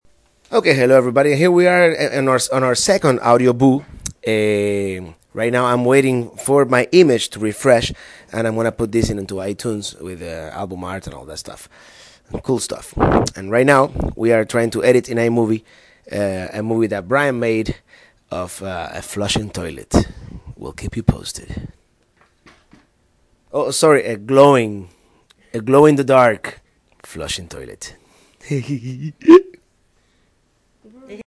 Toilet Flush
35377-toilet-flush.mp3